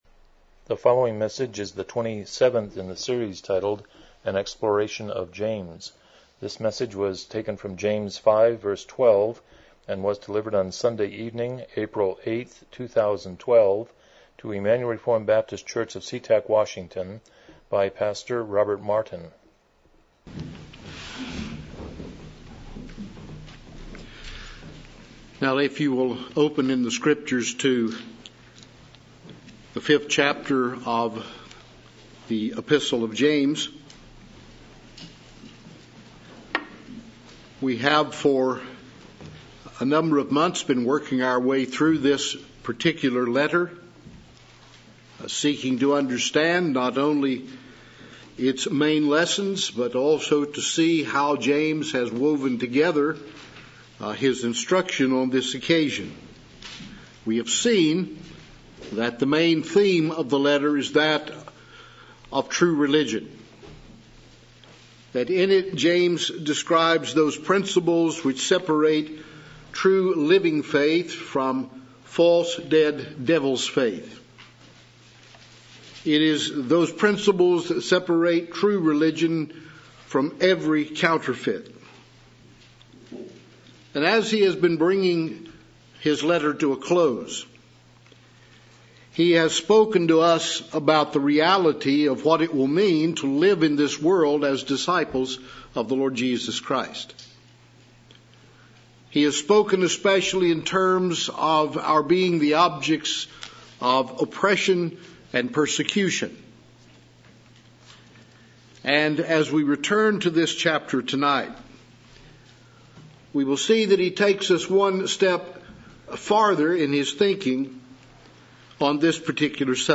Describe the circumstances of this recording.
James 5:12 Service Type: Evening Worship « The Resurrection of Christ and the Judgment to Come 143 Chapter 29.2